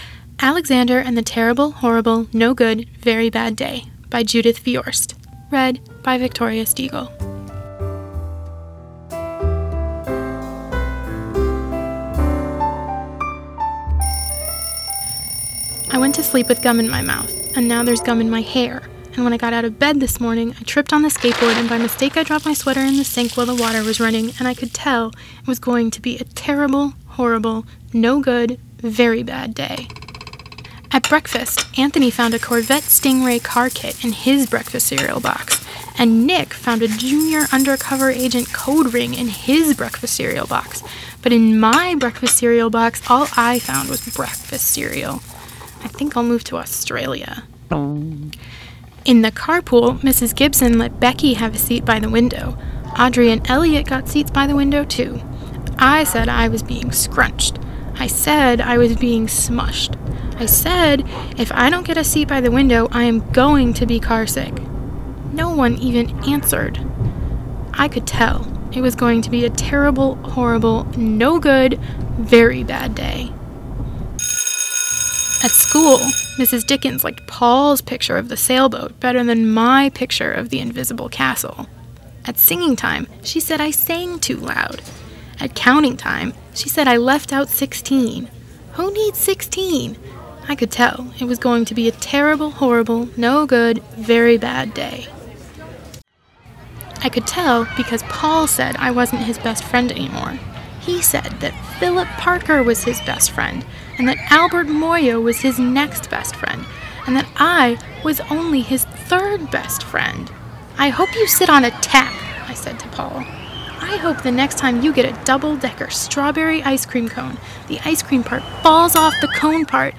Audiobook with Sound Effects
Moving to SoundtrackPro, I added sound effects from the program's library.